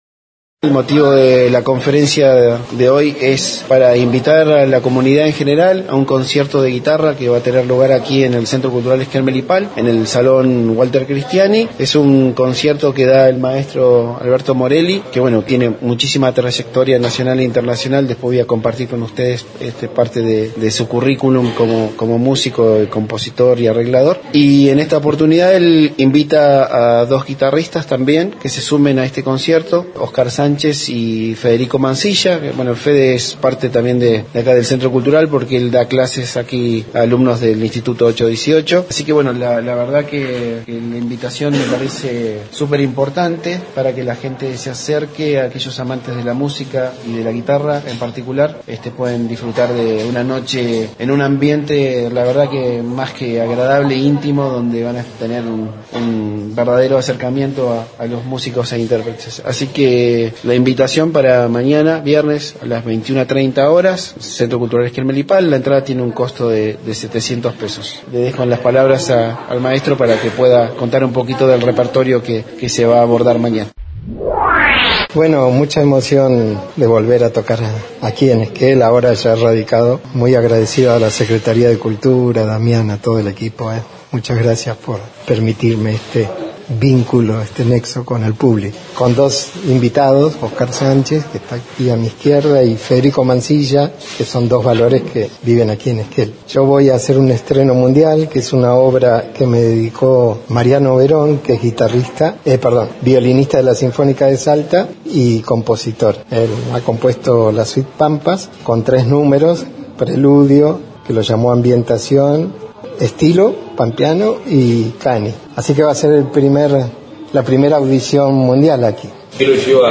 en la conferencia de prensa que realizó acompañado por el Secretario de Cultura Damián Duflos.